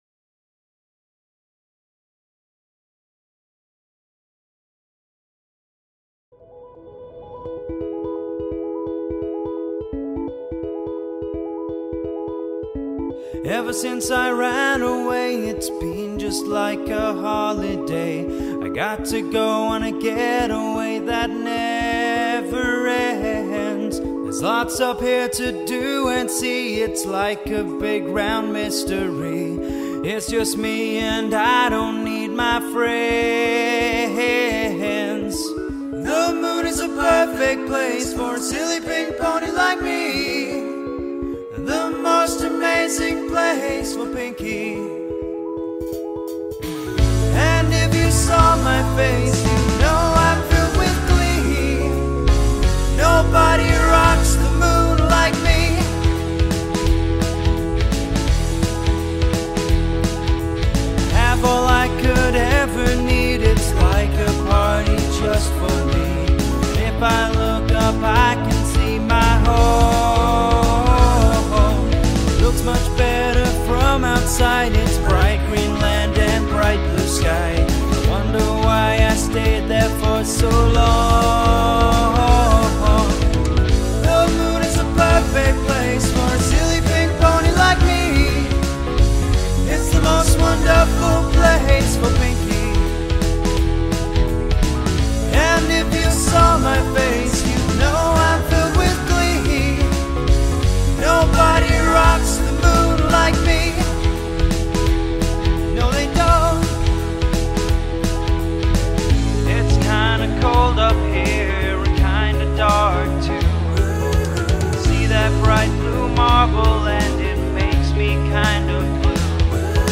lead vocals
background instrumentation, background vocals